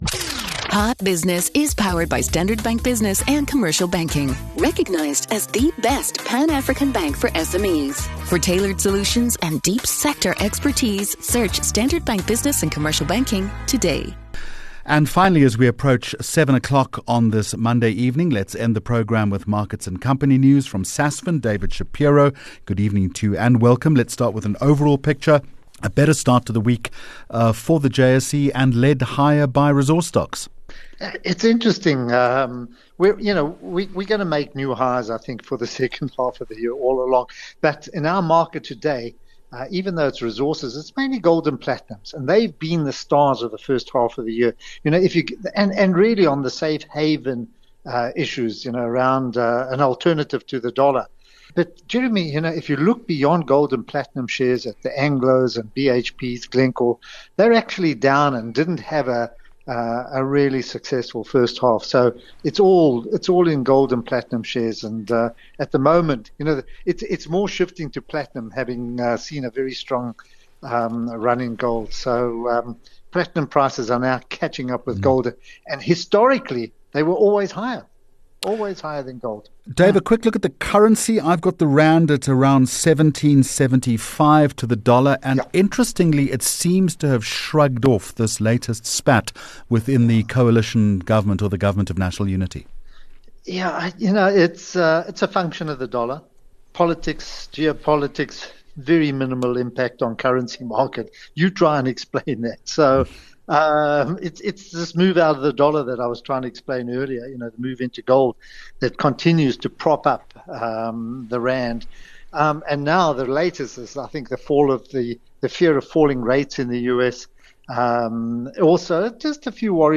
30 Jun Hot Business Interview